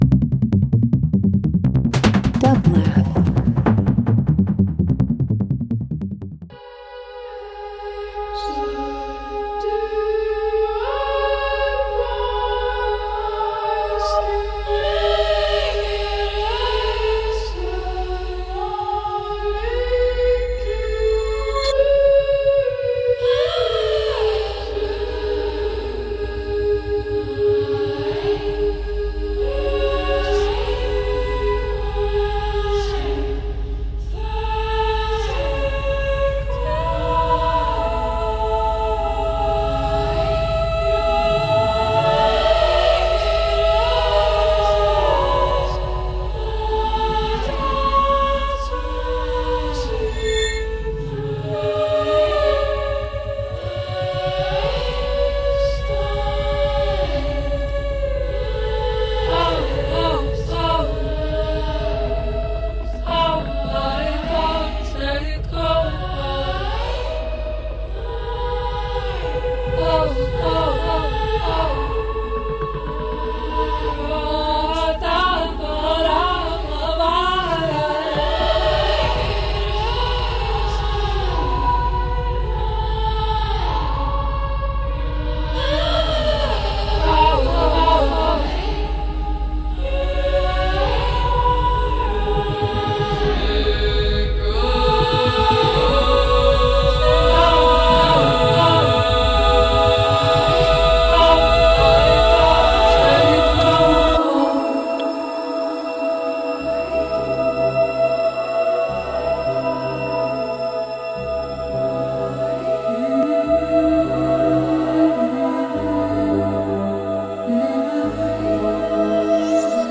Dance House Techno